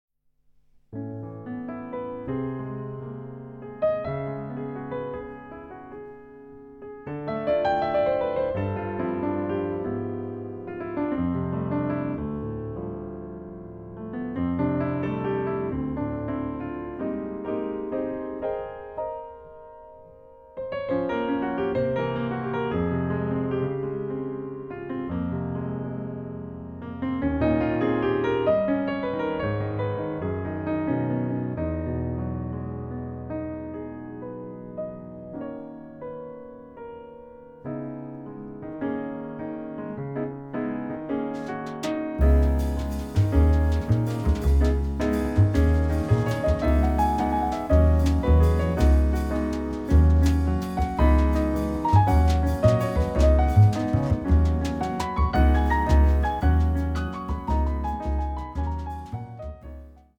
輝き、駆け巡るピアノ。
雪のように舞う美しいピアノと繊細なブラシ・ワークの絡みが見事だ。